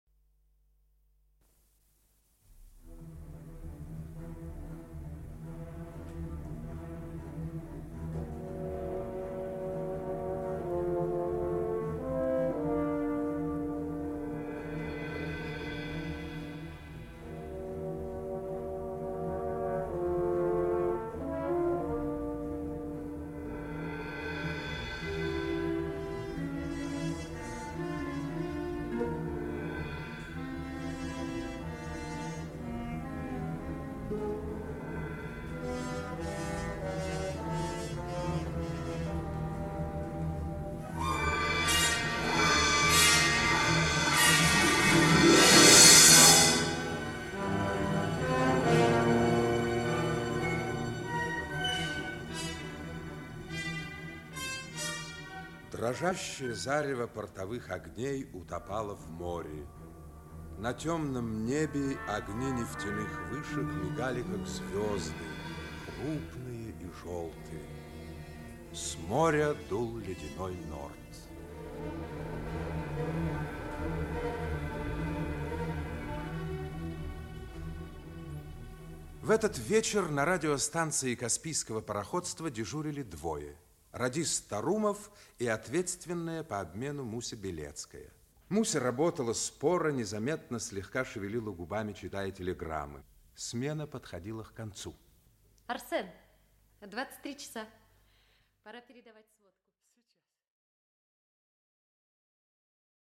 Аудиокнига Танкер «Дербент». Часть 1 | Библиотека аудиокниг
Часть 1 Автор Юрий Крымов Читает аудиокнигу Актерский коллектив.